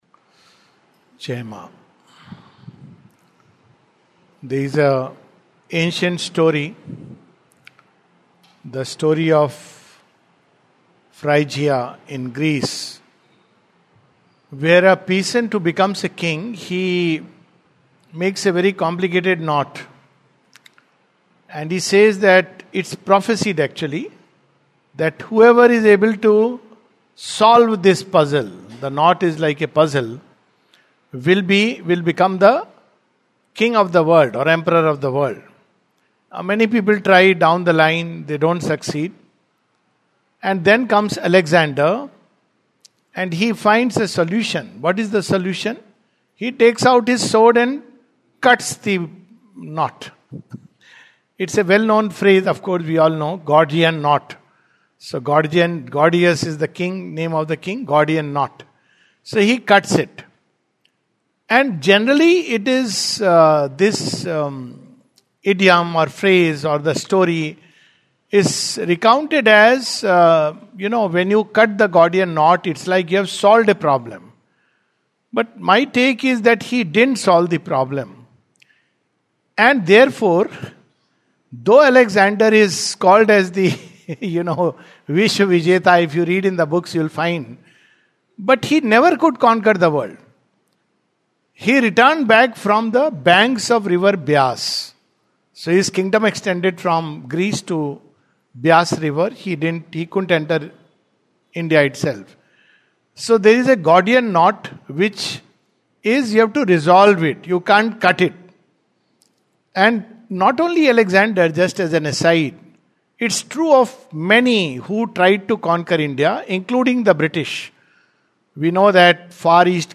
Session # 06-03 at Sri Aurobindo Society, Pondicherry - 605002, India. We continue with Chapter 3 part 1 of Book Two of The Life Divine touching upon the Transcendent, Cosmic and the Individual aspects of existence.